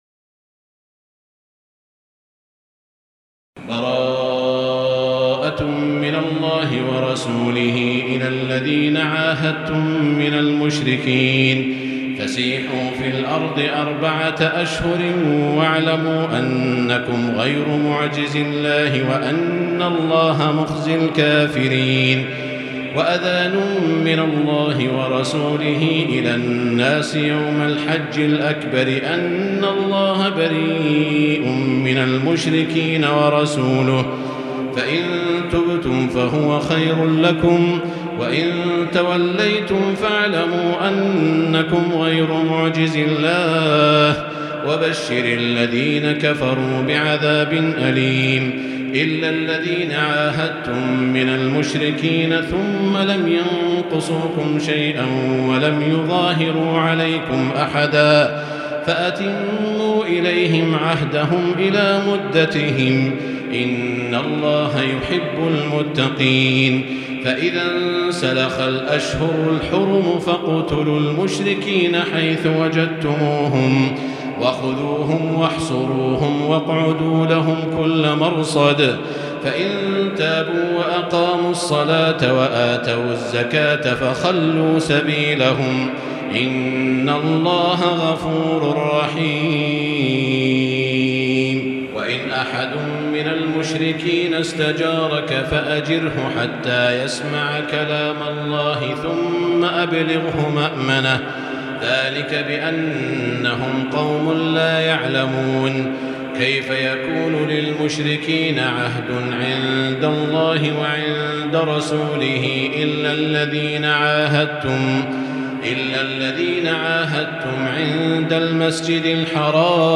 المكان: المسجد الحرام الشيخ: سعود الشريم سعود الشريم معالي الشيخ أ.د. عبدالرحمن بن عبدالعزيز السديس فضيلة الشيخ عبدالله الجهني فضيلة الشيخ ماهر المعيقلي التوبة